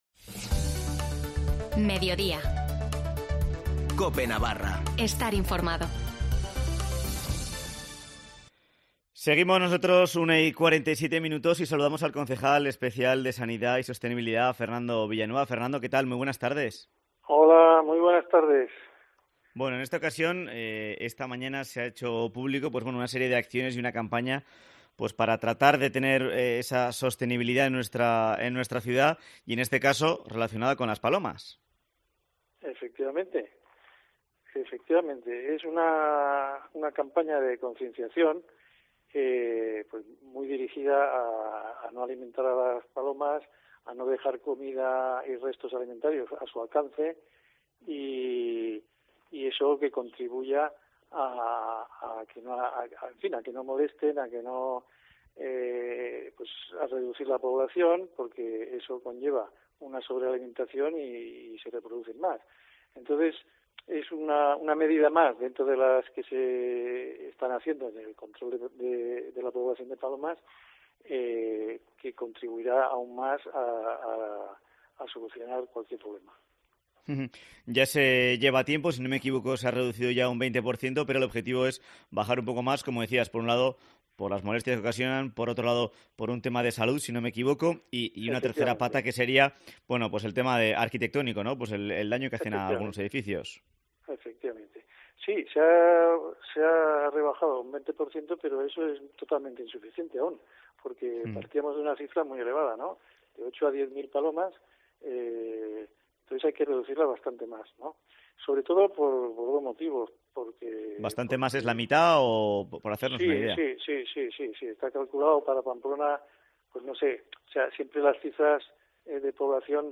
Entrevista con el concejal especial de sanidad y sostenibilidad Fernando Villanueva